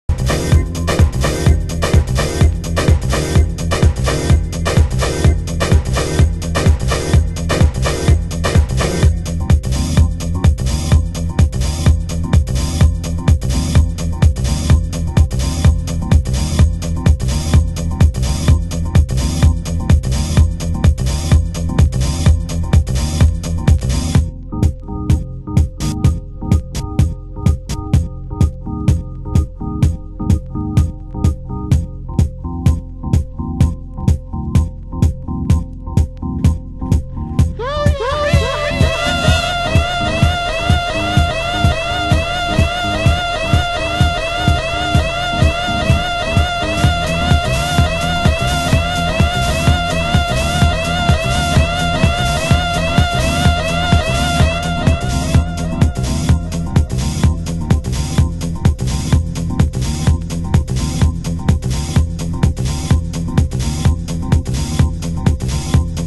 盤質：軽いスレ有/少しチリノイズ有